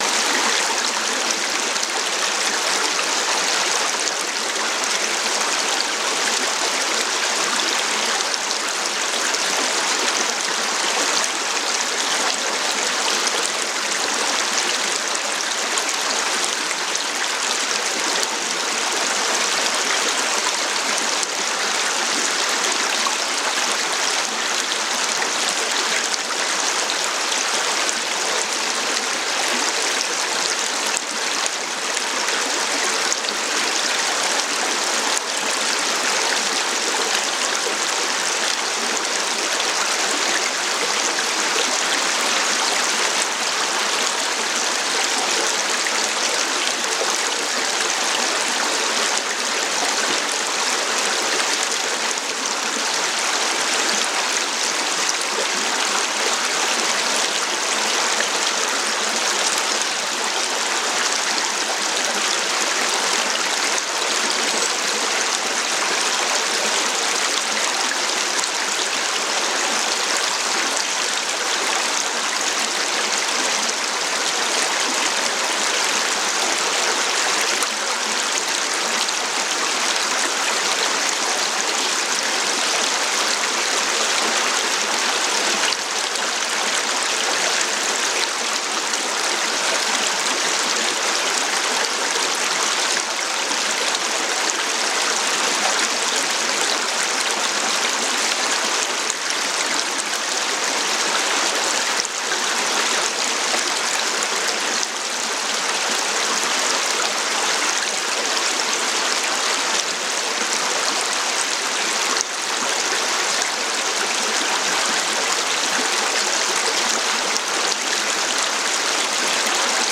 SOFORT-BERUHIGUNG: Waldregen-Seele mit Tropfen für Seele UND Geist
Naturgeräusche